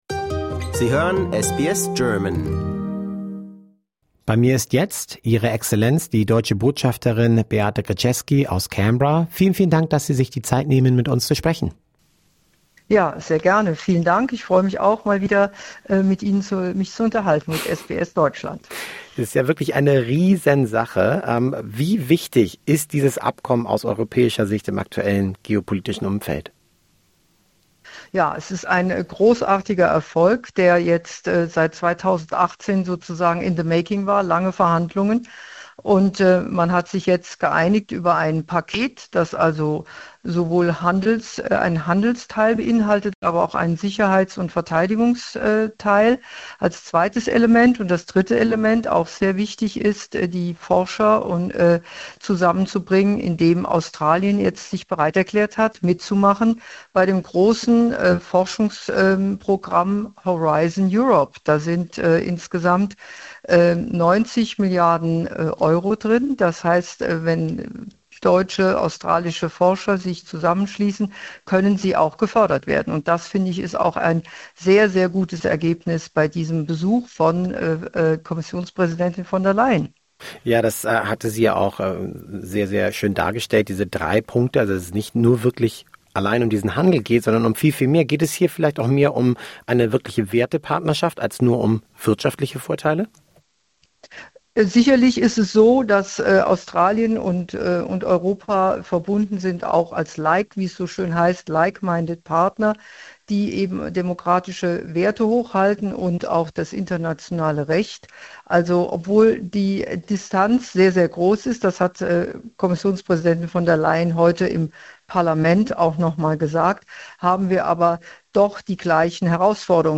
The new trade agreement between the European Union and Australia marks an important step towards a deeper partnership in uncertain geopolitical times. In an interview, German Ambassador Beate Grzeski explains why this is not just about trade and what new opportunities this creates for large and medium-sized German companies.